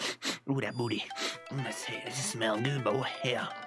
SniffSniff
sniff_vqxsb9o.mp3